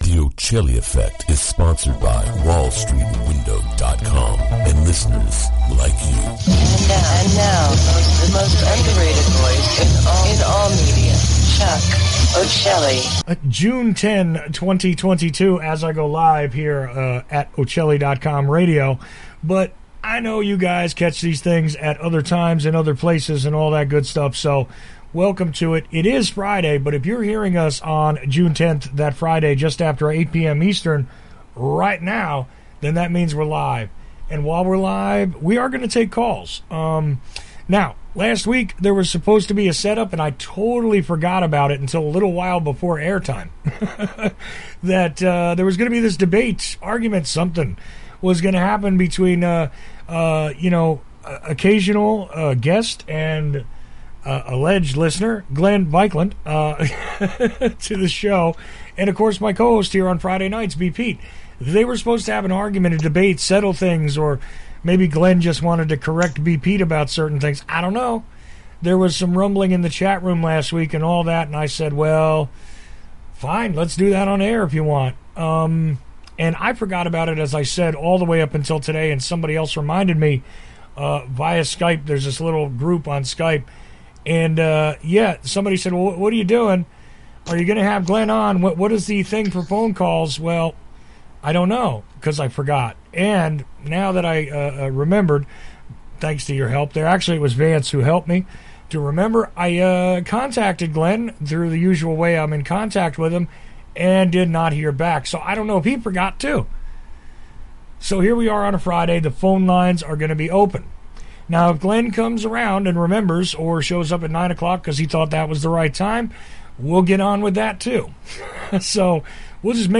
The Friday Night Open Mic went on as usual.
We were lucky to have phone lines working.